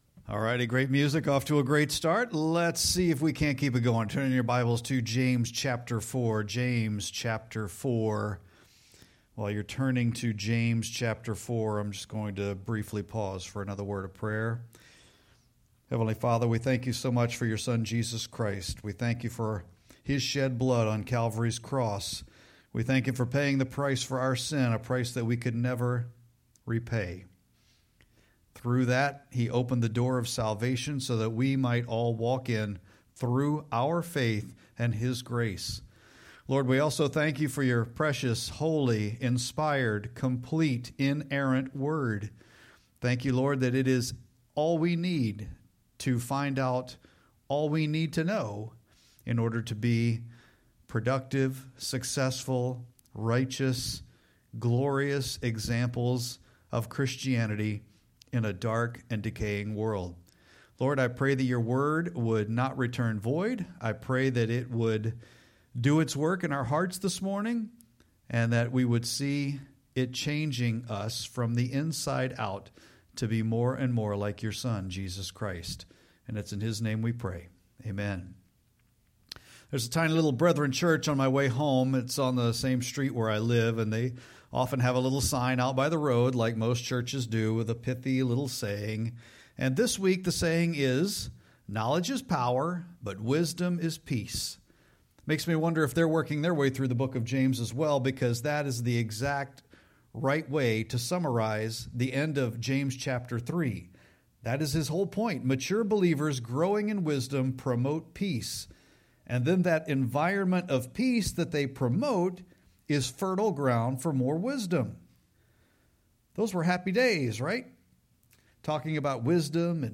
Sermon-8-24-25.mp3